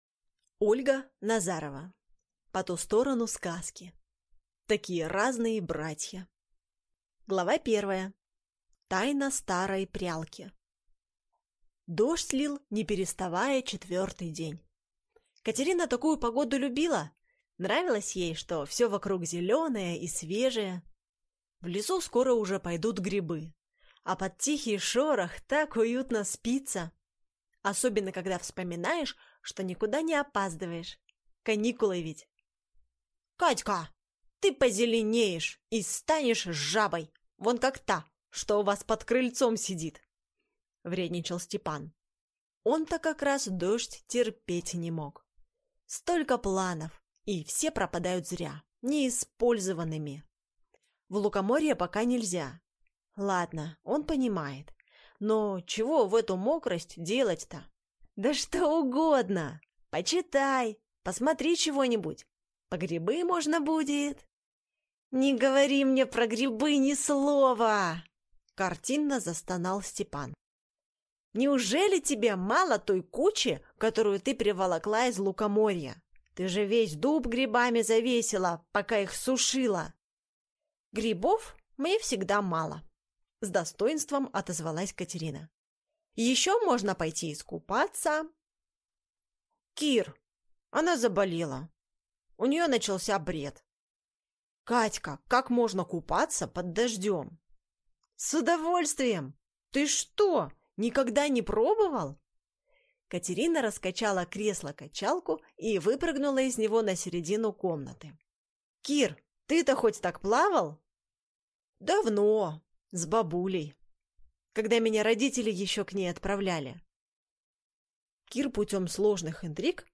Аудиокнига По ту сторону сказки. Такие разные братья | Библиотека аудиокниг